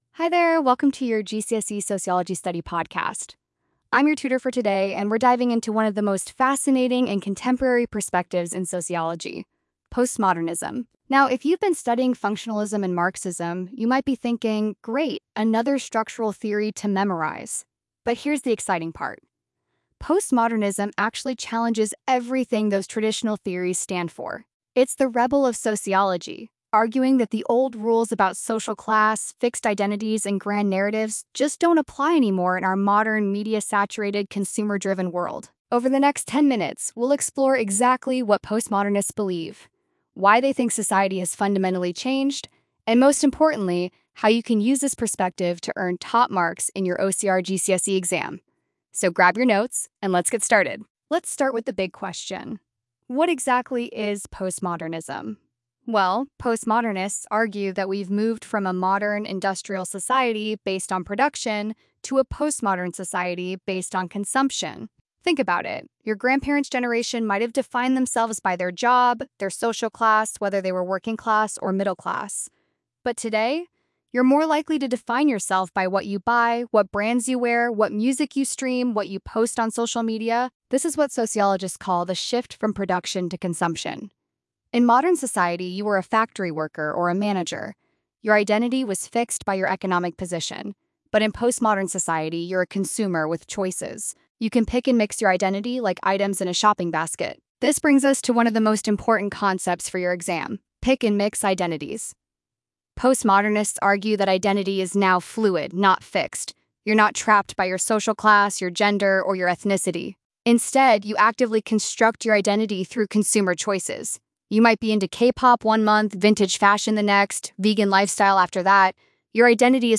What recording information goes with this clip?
Listen to our 10-minute podcast episode for a deep dive into the key concepts, exam tips, and a quick-fire quiz to test your knowledge.